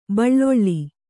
♪ baḷḷoḷḷi